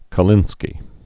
(kə-lĭnskē)